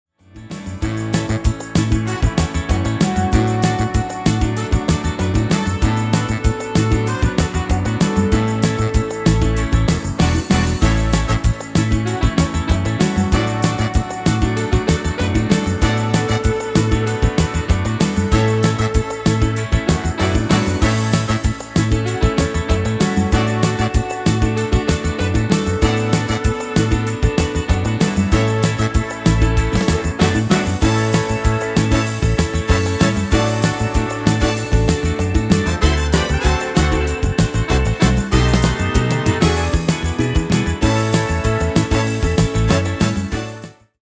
Demo/Koop midifile
Genre: Nederlandse artiesten pop / rock
Toonsoort: A
- Géén vocal harmony tracks
Demo = Demo midifile